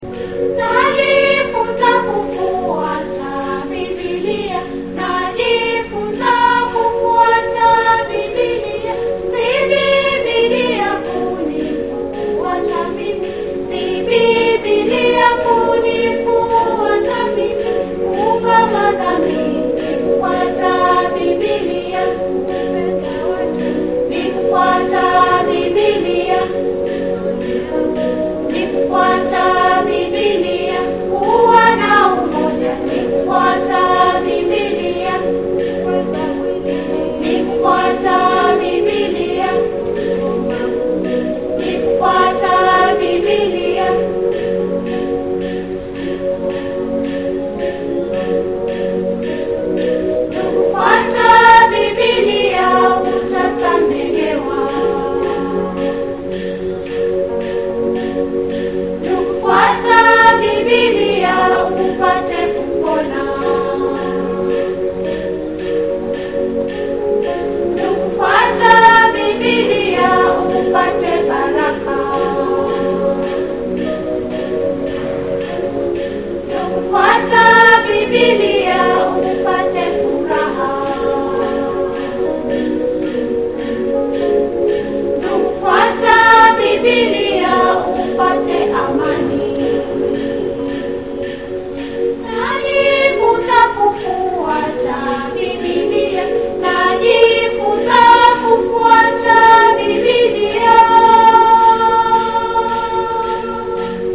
Key F#